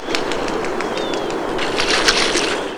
Brown Pelican
Pelecanus occidentalis
VOZ: No vocaliza. El ·nico sonido que mucha gente conoce de esta ave es el ruido de las alas cuando el ave levanta vuelo.